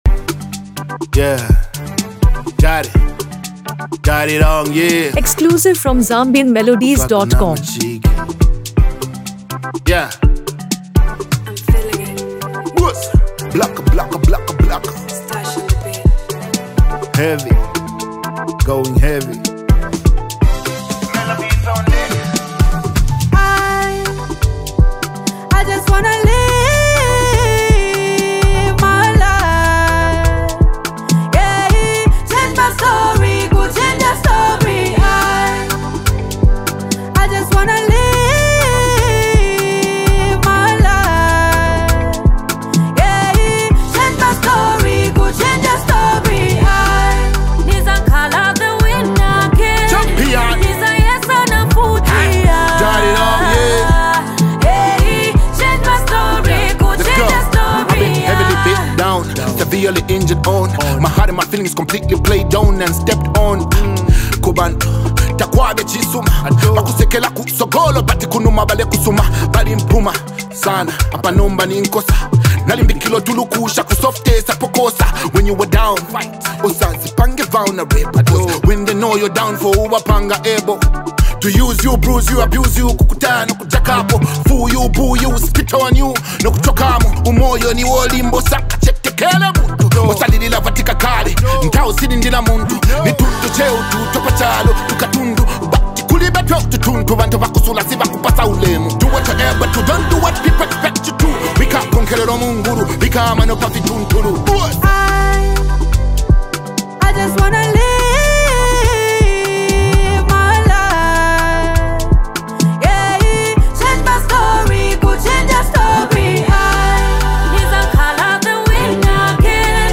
Known for his signature blend of hip-hop and Afro-fusion
harmonious vocals